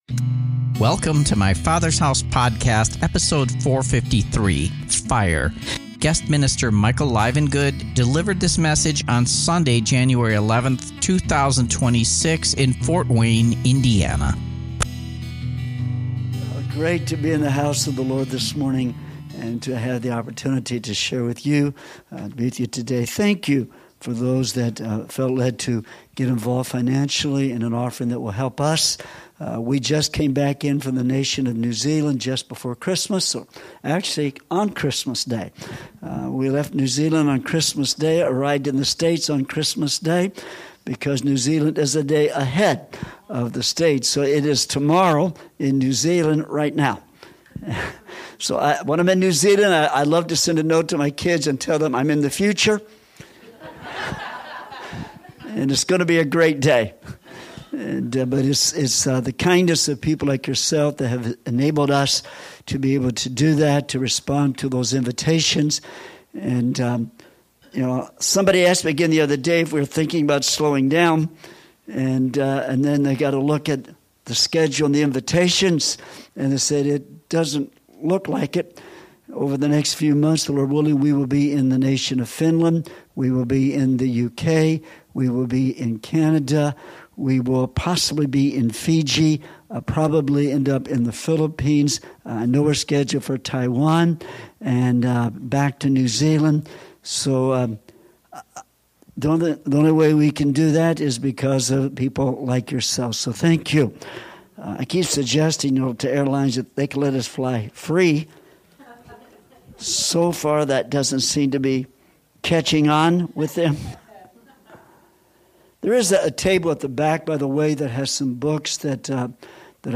Guest minister